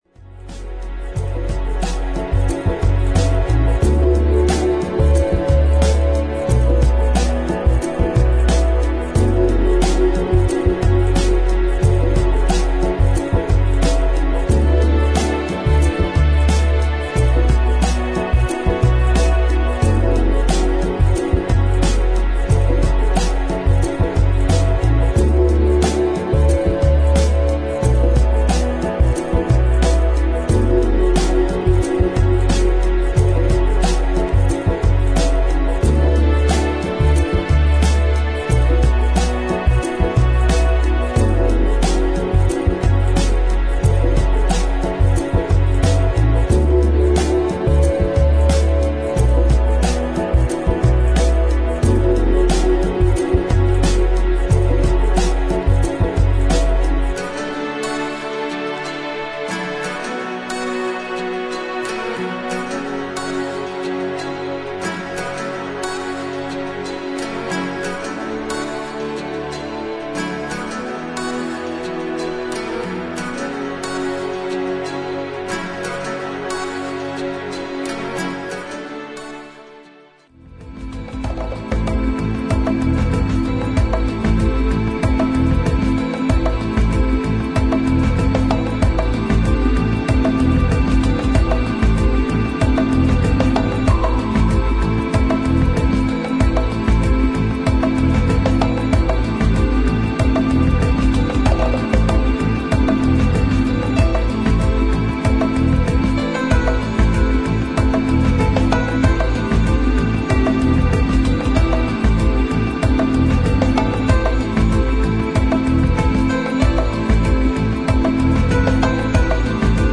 パーカッシブなリズムに乗る、優美なストリングスとギターの絡みが心地良い